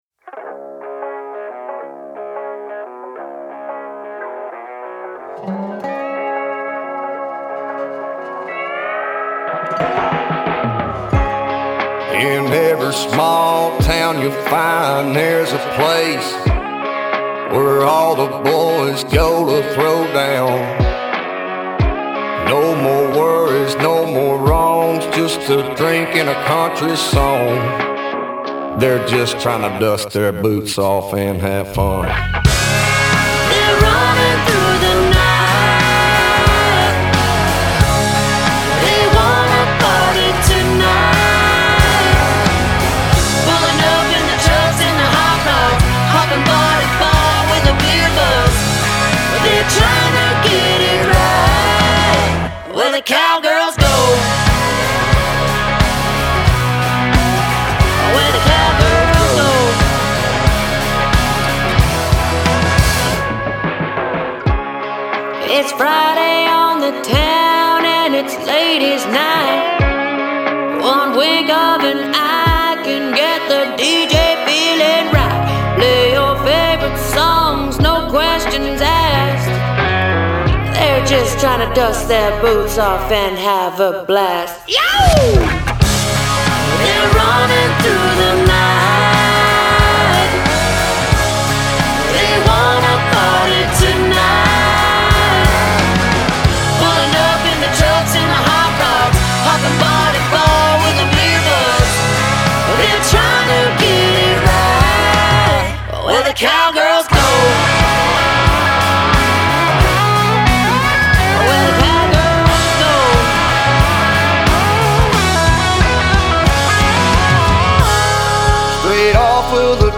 American Country